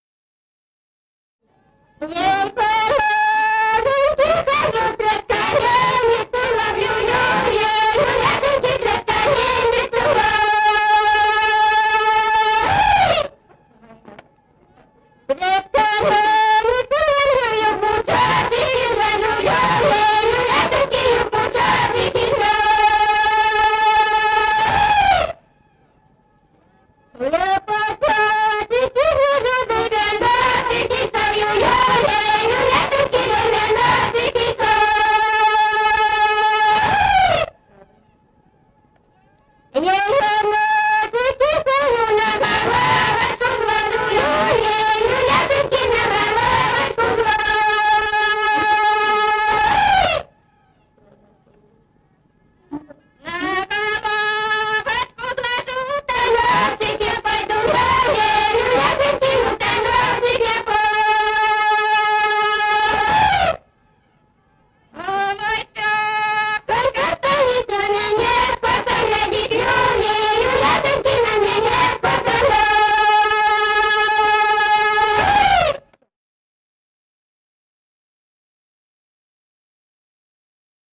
Народные песни Стародубского района «Я по луженьке хожу», духовская таночная.
1954 г., с. Курковичи.